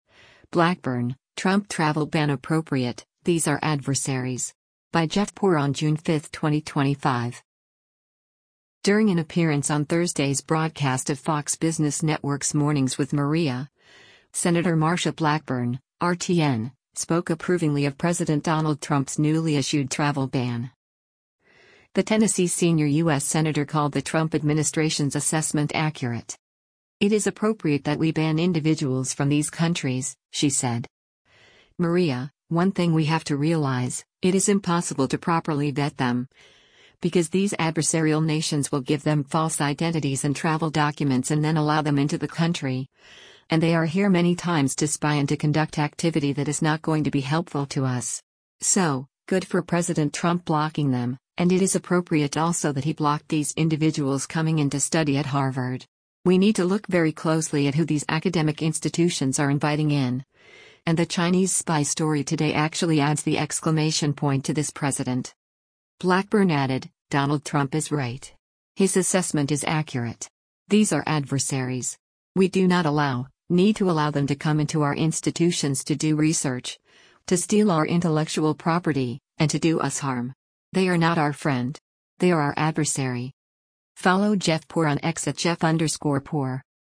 During an appearance on Thursday’s broadcast of Fox Business Network’s “Mornings with Maria,” Sen. Marsha Blackburn (R-TN) spoke approvingly of President Donald Trump’s newly issued travel ban.